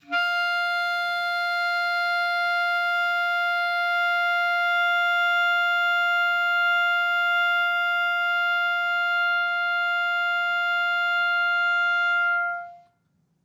Clarinet
DCClar_susLong_F4_v3_rr1_sum.wav